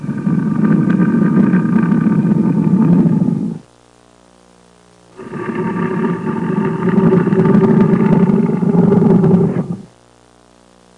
Tiger Sound Effect
Download a high-quality tiger sound effect.
tiger.mp3